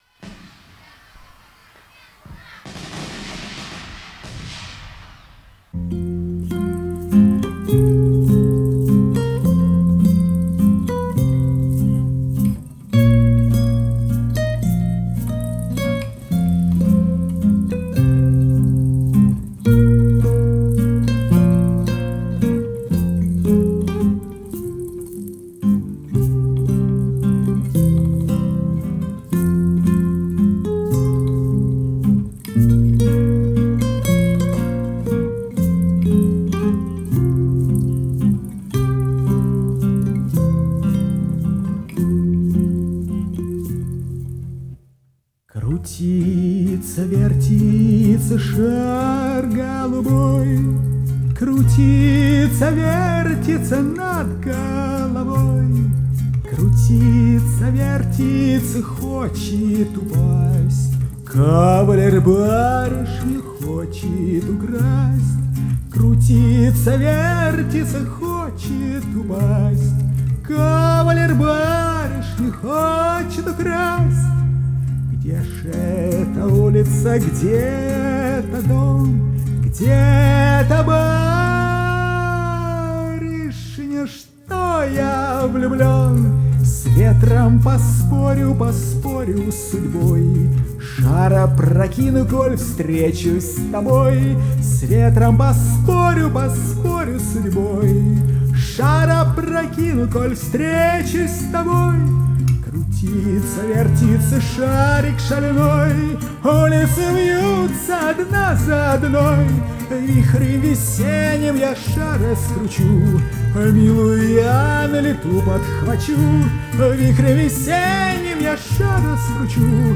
Дуэт двух мульти-инструменталистов
Жанр: Фолк, Шансон, Романс, Акустика